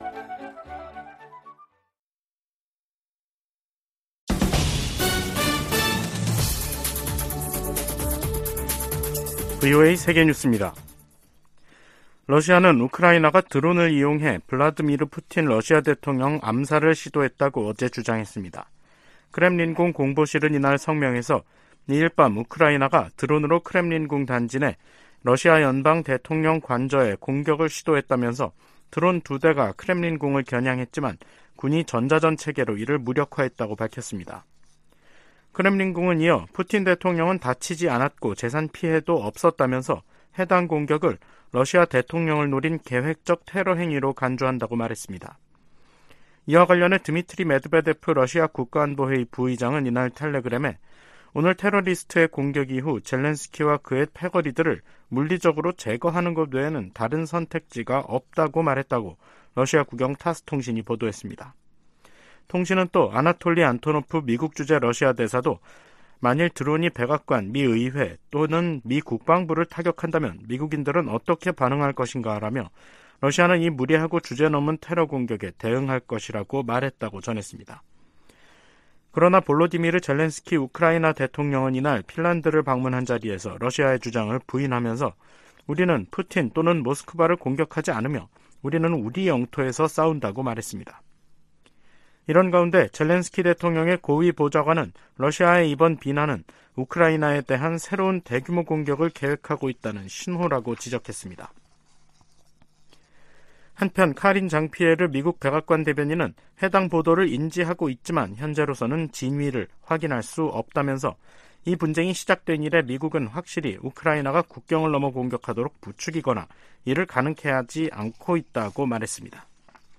VOA 한국어 간판 뉴스 프로그램 '뉴스 투데이', 2023년 5월 4일 2부 방송입니다. 미 국무부가 기시다 후미오 일본 총리의 한국 방문 계획을 환영했습니다.